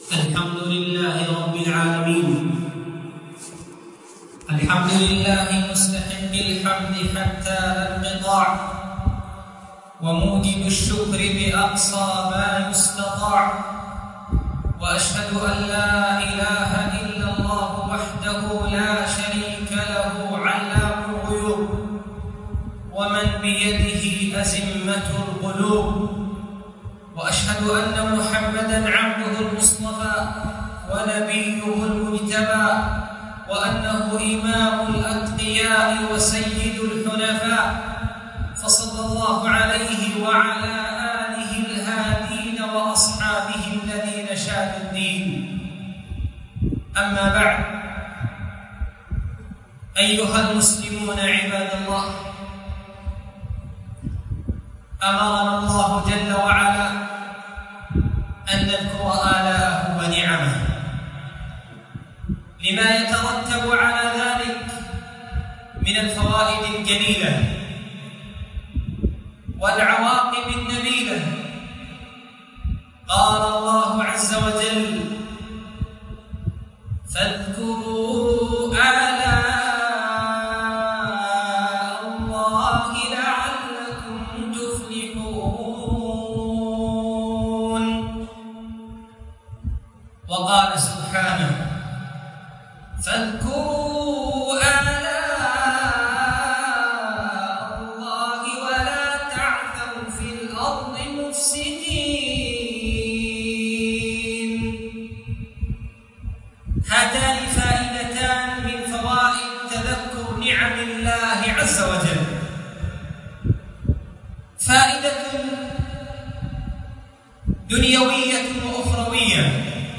مسجد درة عدن / محافظة عدن حرسها الله 17 / ربيع أول 1446